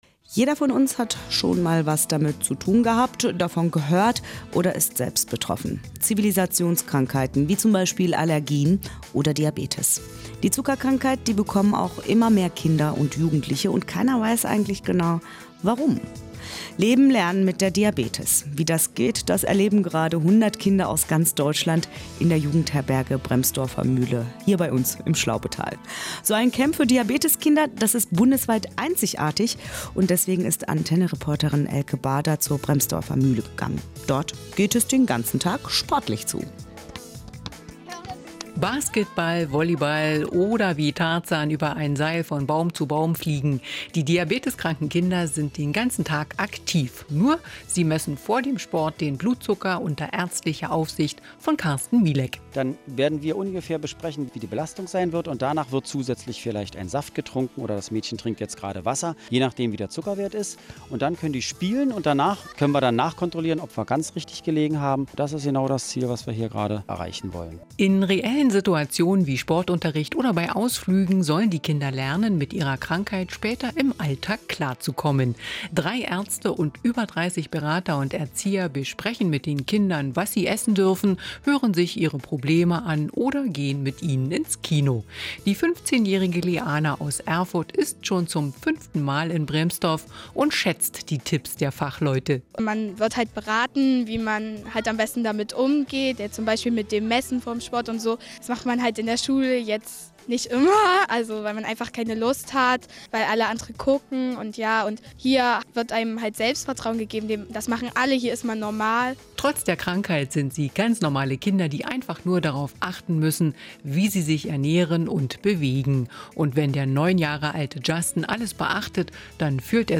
Der  Zuschnitt der Aufnahme des rbb- Rundfunk vom 31.07.2012 in der Jugendherberge